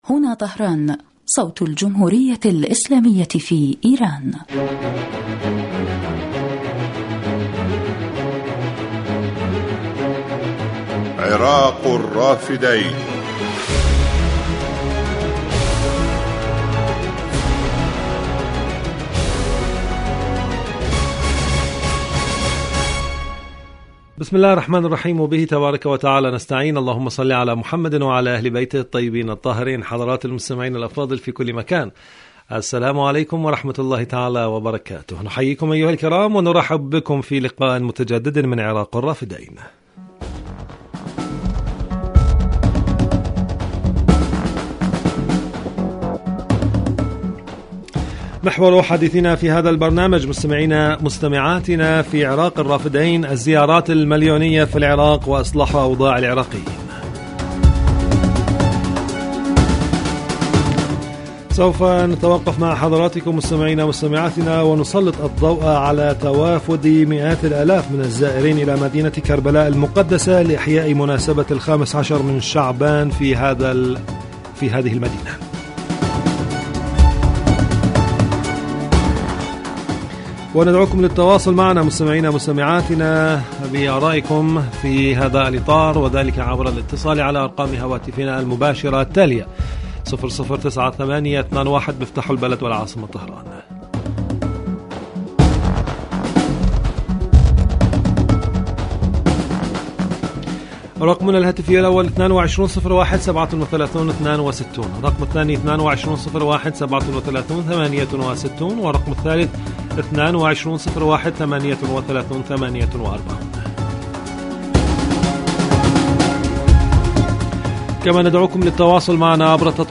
الزيارات المليونية في العراق وإصلاح أوضاع العراقيين Arabic Radio 21 views 20 March 2022 Embed likes Download إذاعة طهران-عراق الرافدين